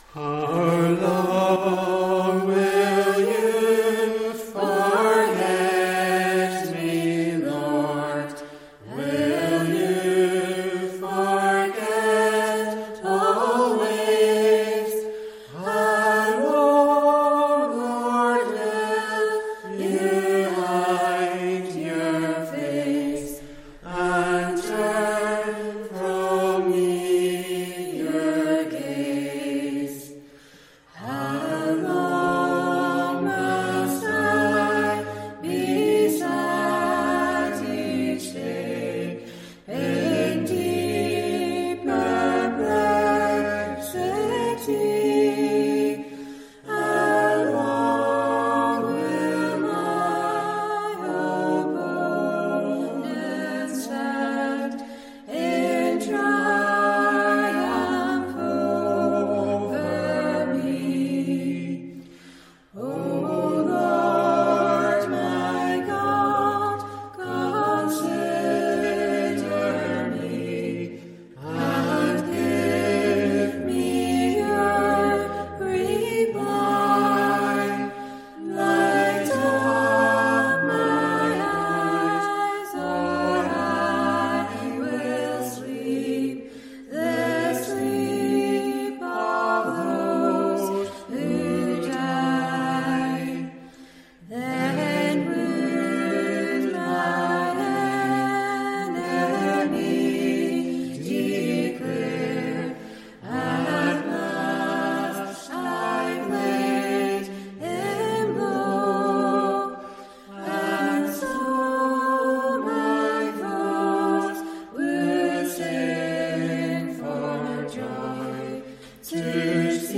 Congregational Psalm Singing
Recorded during the first lockdown when church services went on-line. We had to choose between pumping out YouTube clips or five of us singing live at a microphone built for one.